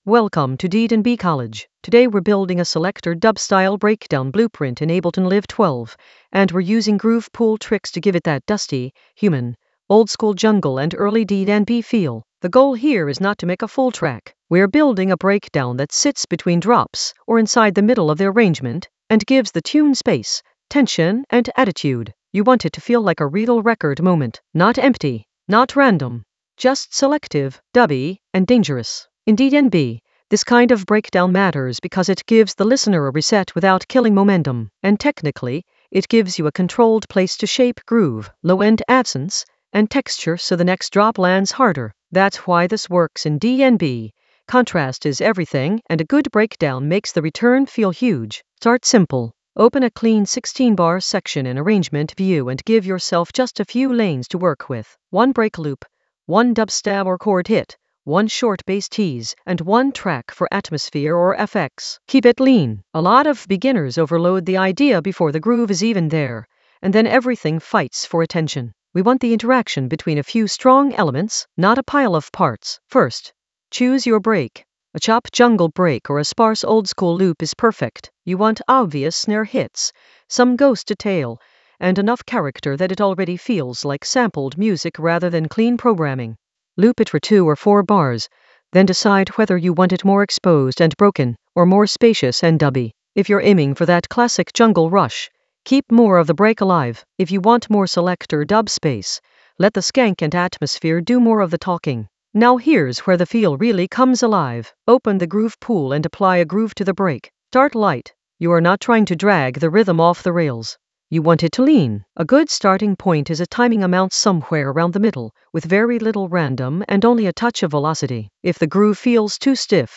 An AI-generated beginner Ableton lesson focused on Selector Dub Ableton Live 12 a breakdown blueprint using groove pool tricks for jungle oldskool DnB vibes in the Workflow area of drum and bass production.
Narrated lesson audio
The voice track includes the tutorial plus extra teacher commentary.